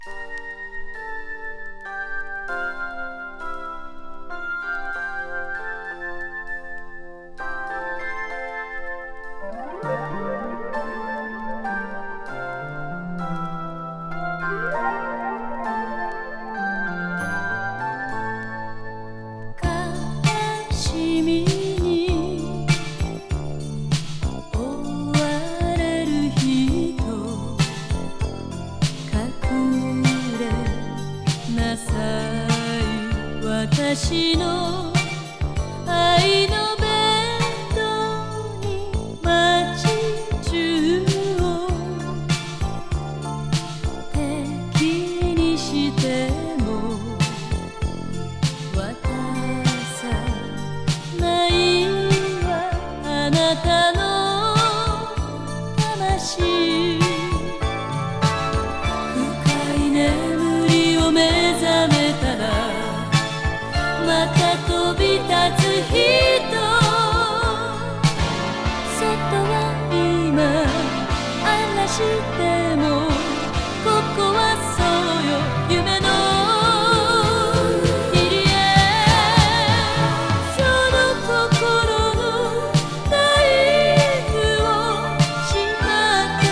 アンニュイ・和レアリック